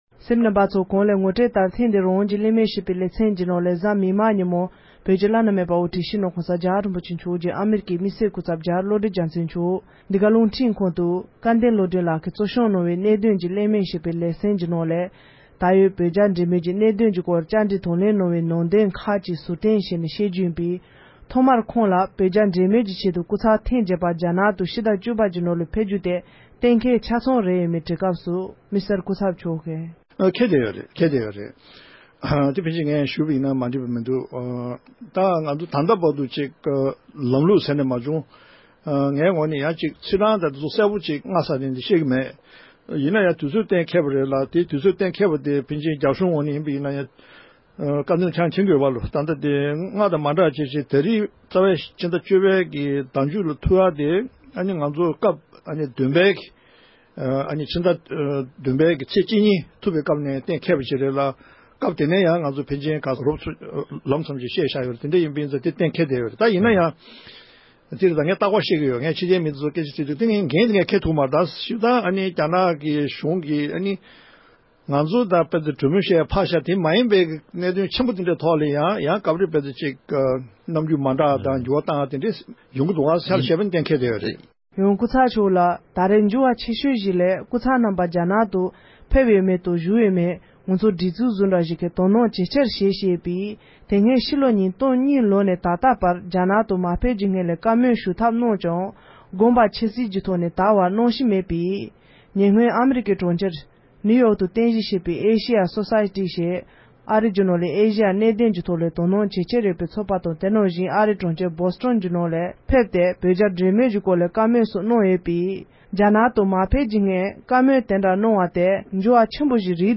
༸གོང་ས་མཆོག་གི་སྐུ་ཚབ་རྒྱ་རི་བློ་གྲོས་རྒྱལ་མཚན་རིན་པོ་ཆེ་མཆོག་གིས་རྒྱ་བོད་འབྲེལ་མོལ་ཆེད་རྒྱ་ནག་ཏུ་ཕེབས་རྒྱུ་སོགས་ཀྱི་སྐོར་གསུངས་བ།